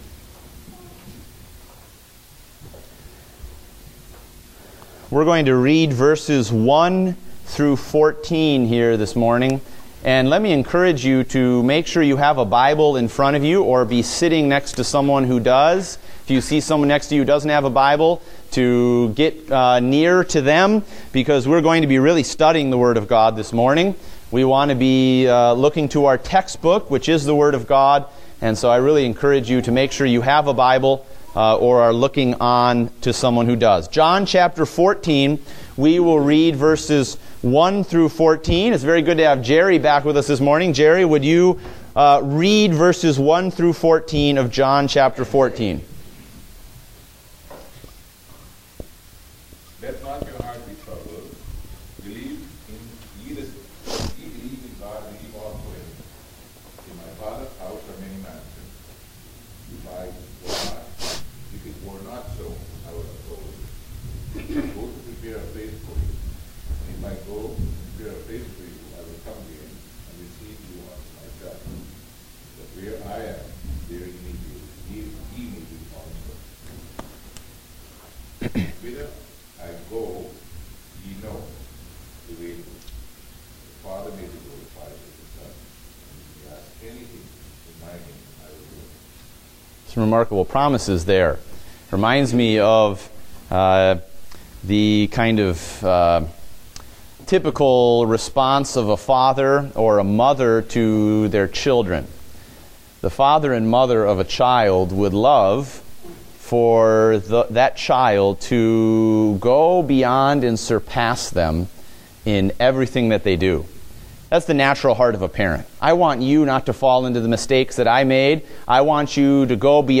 Date: April 10, 2016 (Adult Sunday School)